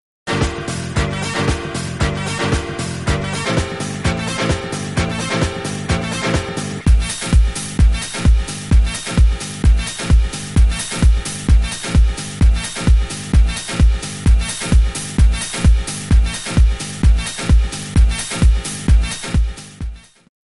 On boucle…